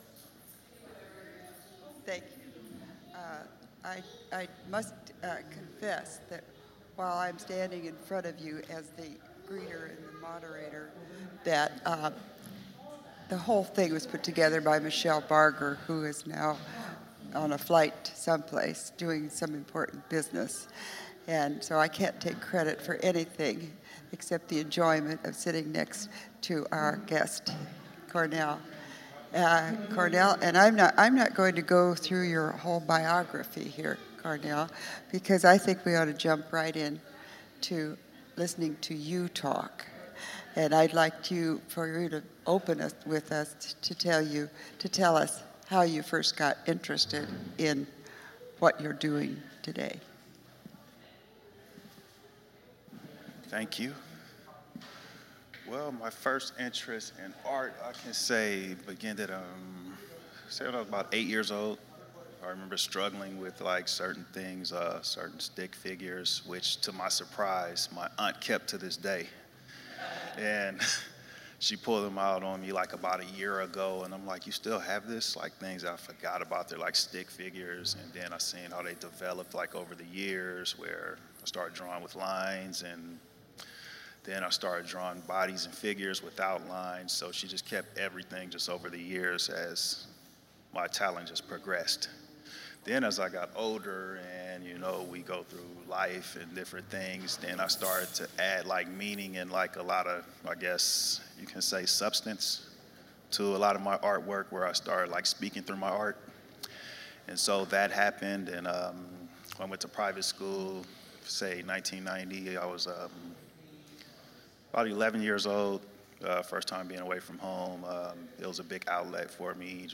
Venue Sanctuary